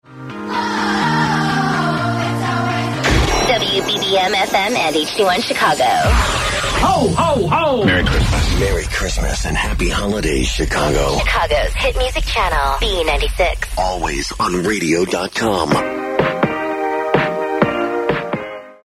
WBBM-FM Top of the Hour Audio: